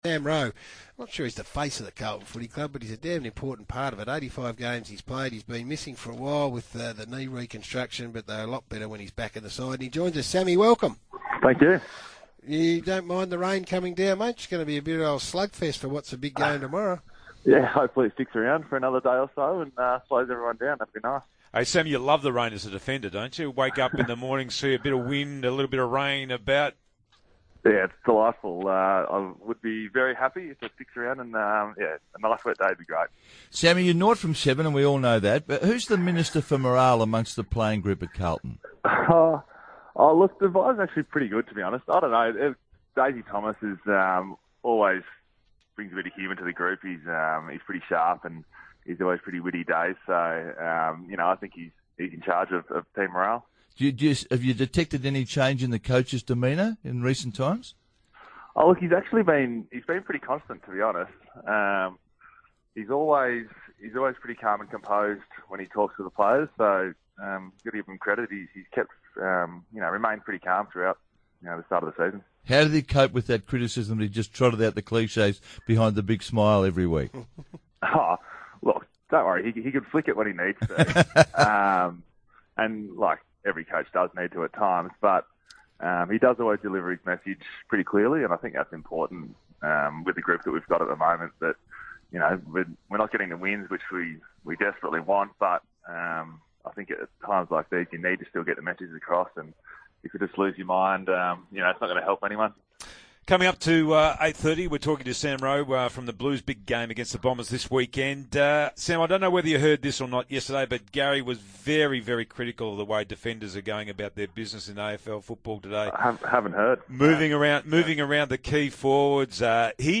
Carlton defender Sam Rowe chats to SEN 1116 ahead of the Blues' Round 8 clash against Essendon.